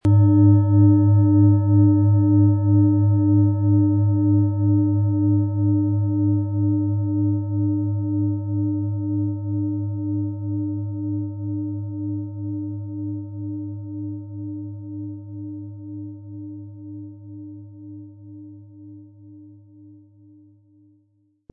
Planetenton 1
Planetenschale® Besser einschlafen & Mystische Energie wahrnehmen mit Neptun, Ø 24,5 cm inkl. Klöppel
Im Sound-Player - Jetzt reinhören können Sie den Original-Ton genau dieser Schale anhören.
GewichtCa. 1415 gr
MaterialBronze